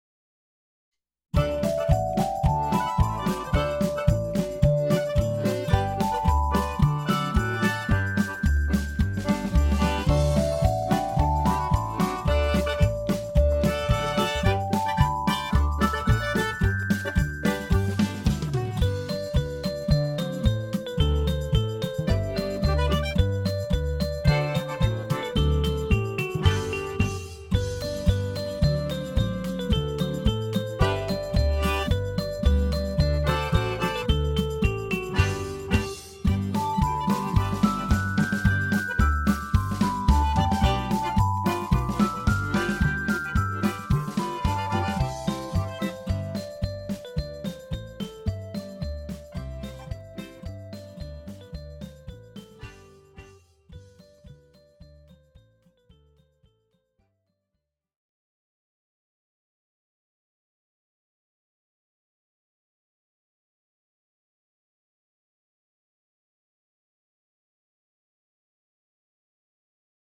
Neliels skaņdarbs ar fonogrammu